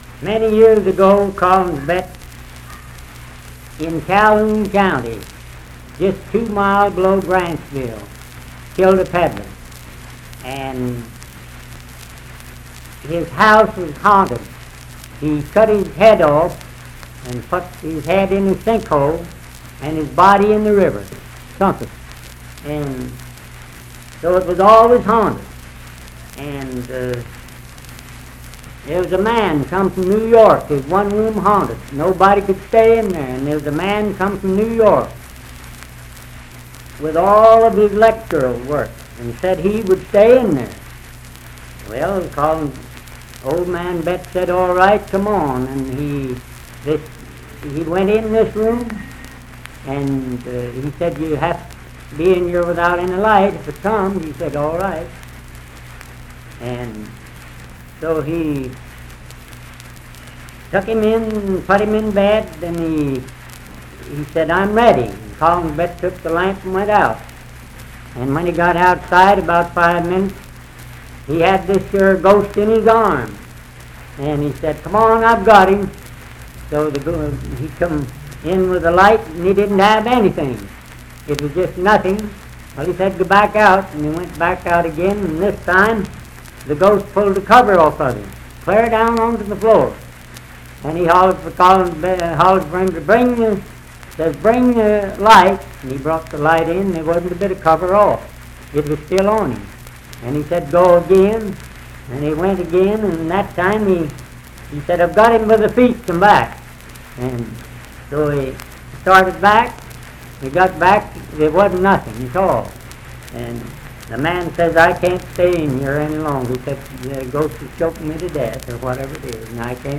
Unaccompanied vocal music and folktales
Folklore--Non Musical
Voice (sung)